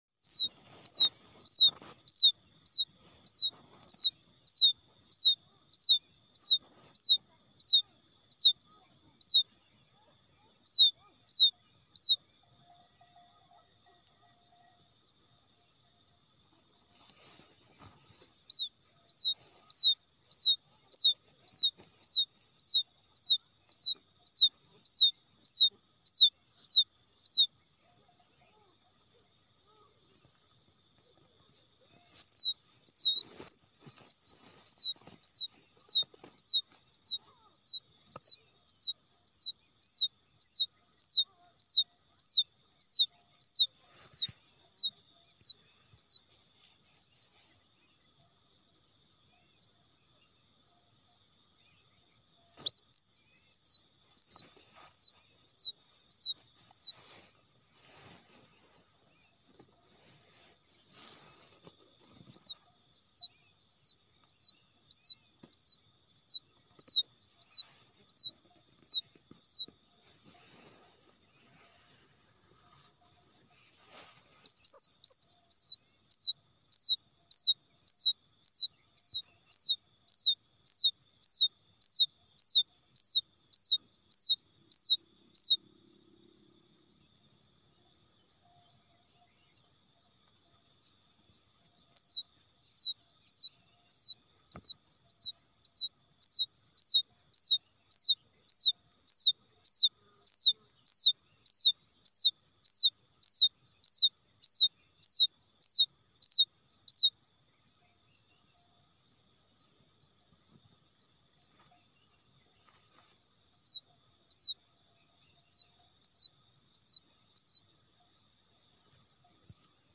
เสียงนกคุ้มอืด (หลงกับแม่) ชัดที่สุด เข้าเร็ว 100% พลัด
เสียงต่อพังพอน เสียงนกคุ้มอืด (ตัวเมีย)
หมวดหมู่: เสียงนก